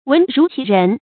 文如其人 注音： ㄨㄣˊ ㄖㄨˊ ㄑㄧˊ ㄖㄣˊ 讀音讀法： 意思解釋： 指文章的風格同作者的性格特點相似。